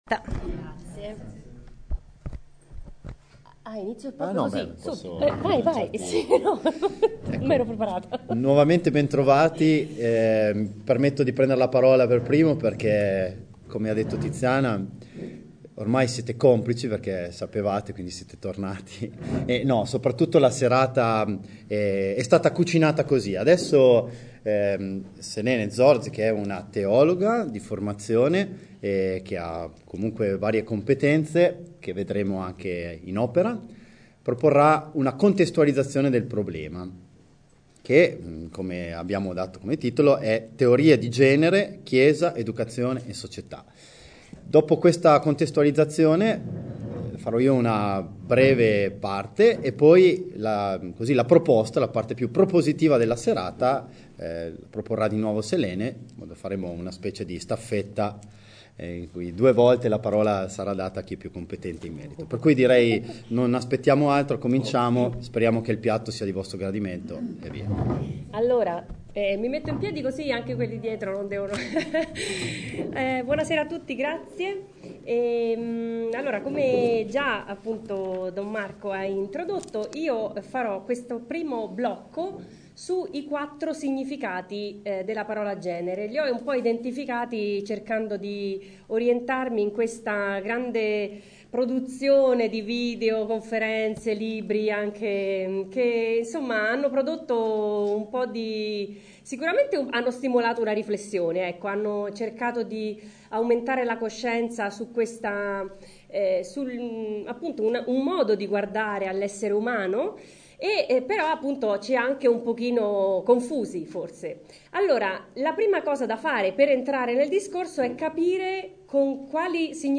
REGISTRAZIONE DELL’INCONTRO ALLA PAGINA: